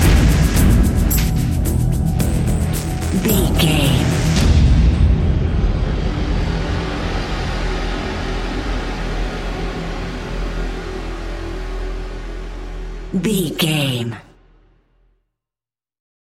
Ionian/Major
E♭
industrial
dark ambient
synths